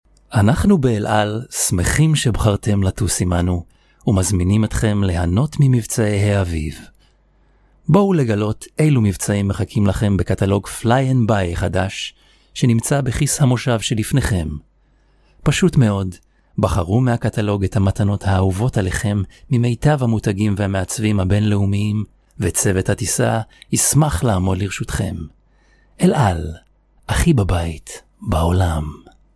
Hebrew, Middle Eastern, Male, 20s-40s